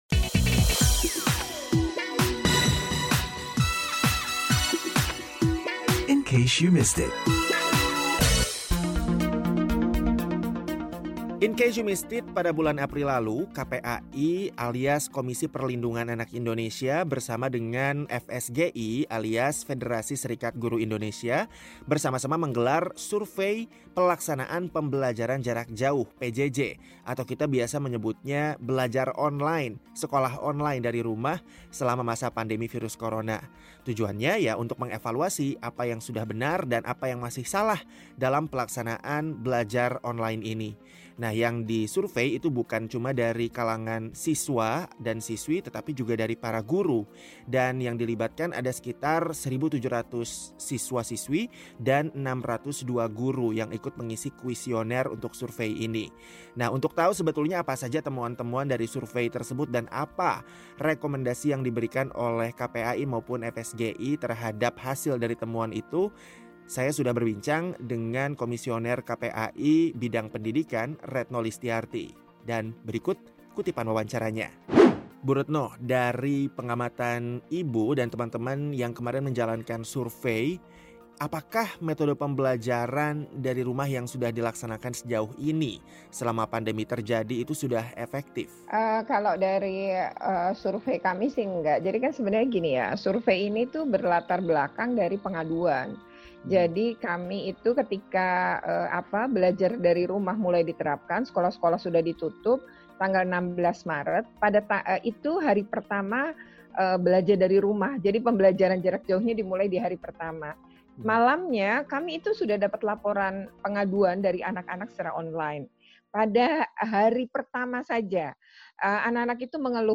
berbincang dengan Komisioner KPAI Bidang Pendidikan, Retno Listyarti